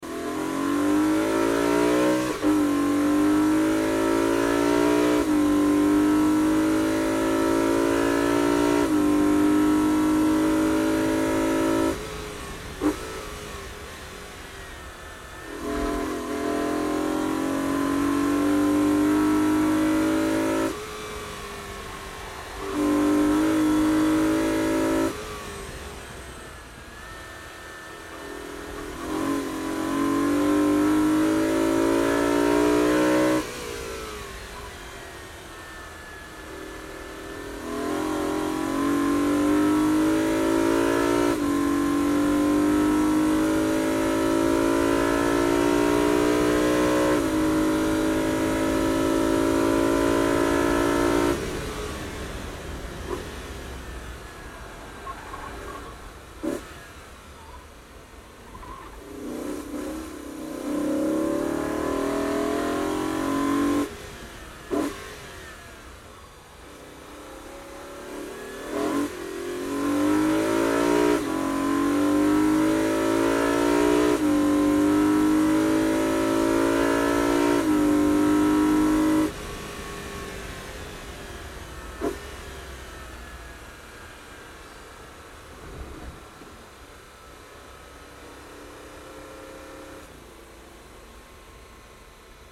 Звуки гоночного автомобиля
Звук гоночной BMW: мощное рычание, атмосферная скорость